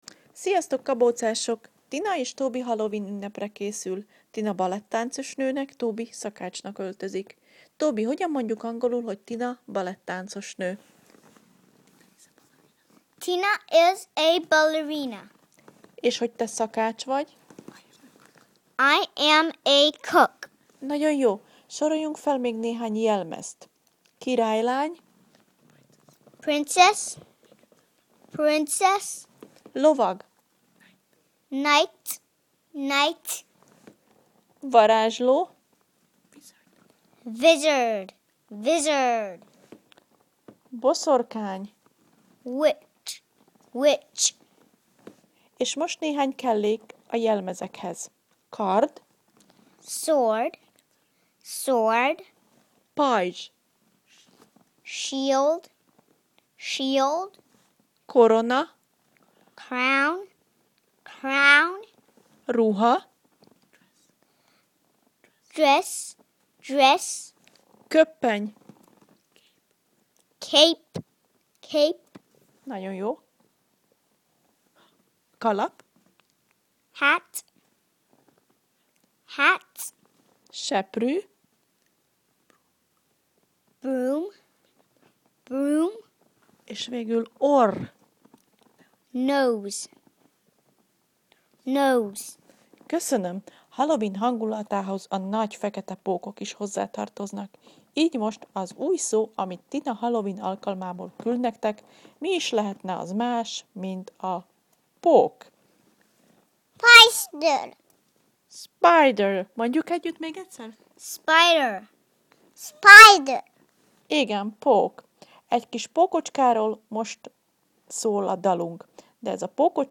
TobyAz ebben a leckében szereplő szavak helyes kiejtését hallgasd meg Tobytól és Tinától